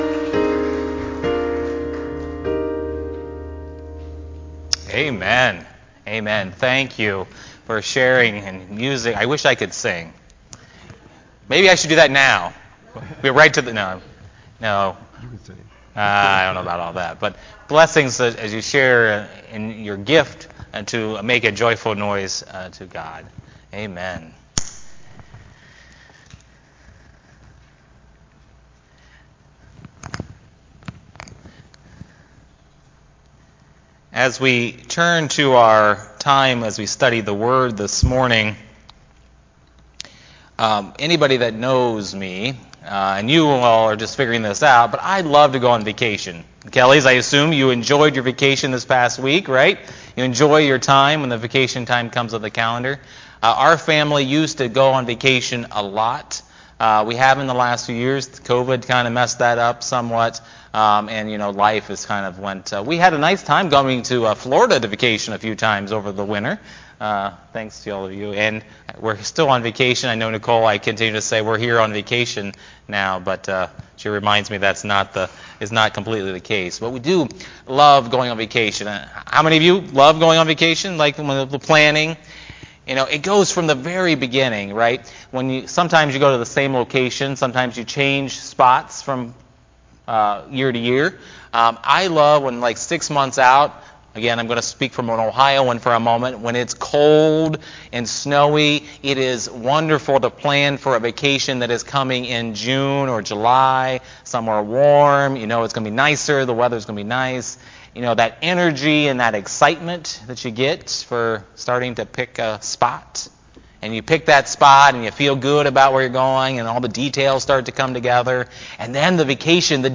Online Sunday Service
Sermon